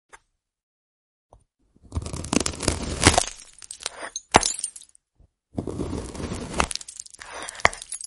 Cutting A Jewel Shortcake🍰 – Sound Effects Free Download